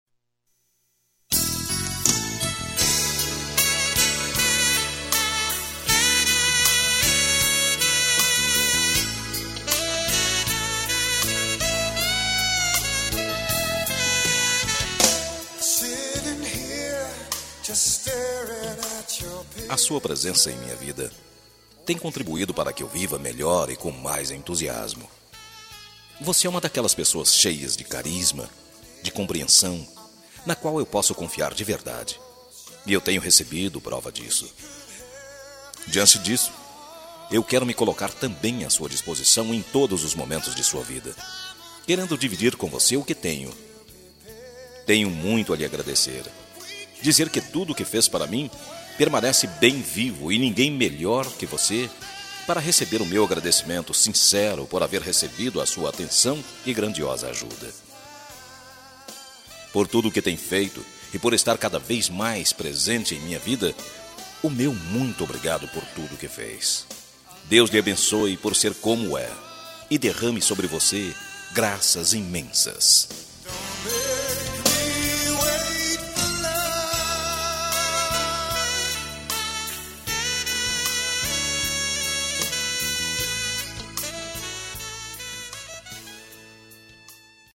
Agradecimento – Voz Masculina – Cód: 09814